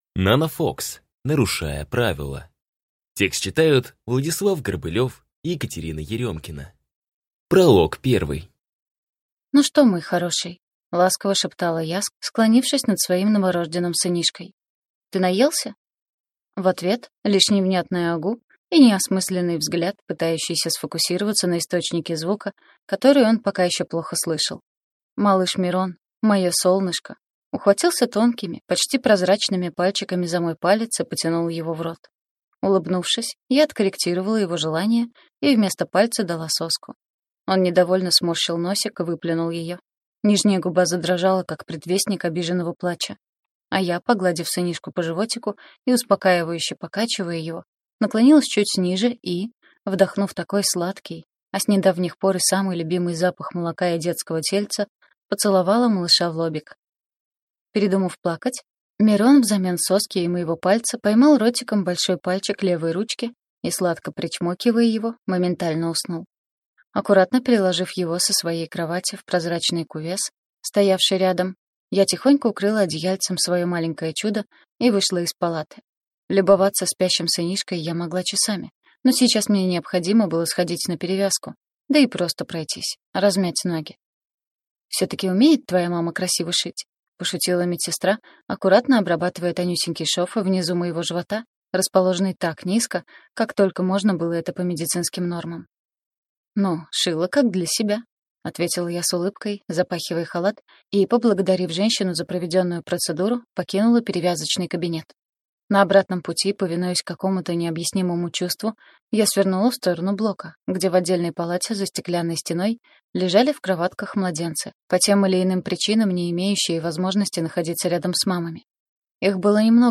Аудиокнига Нарушая правила | Библиотека аудиокниг